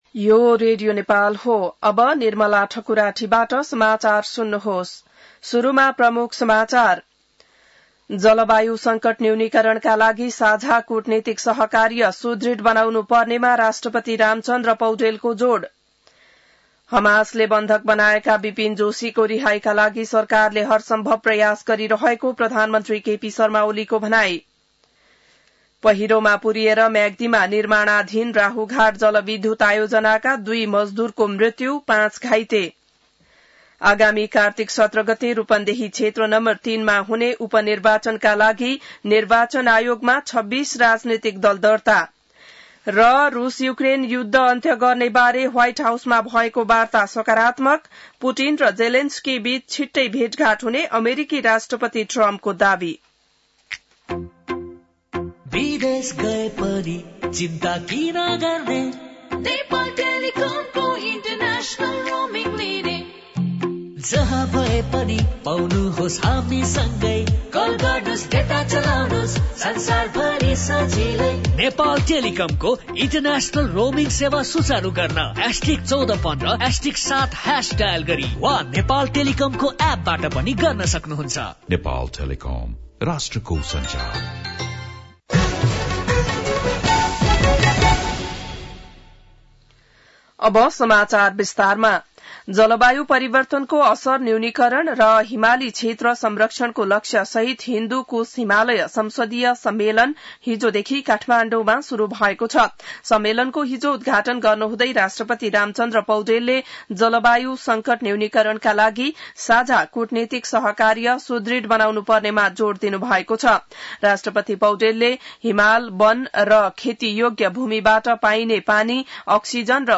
बिहान ७ बजेको नेपाली समाचार : ३ भदौ , २०८२